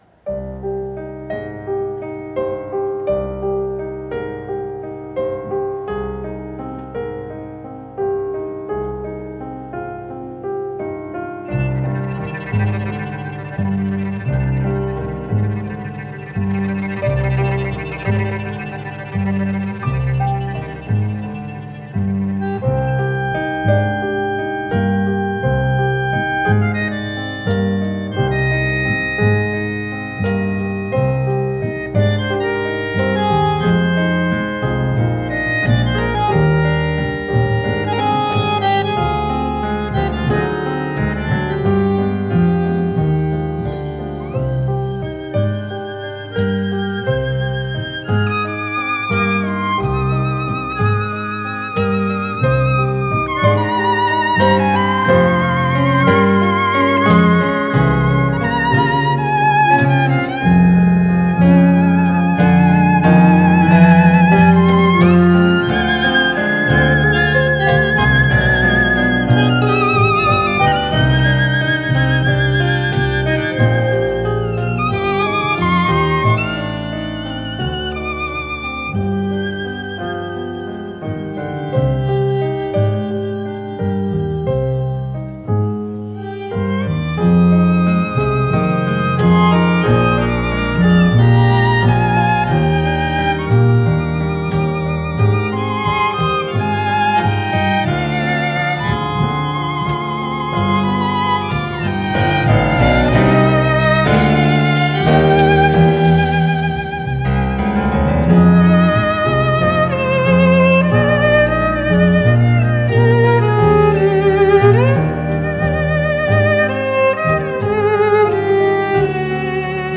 Танго
live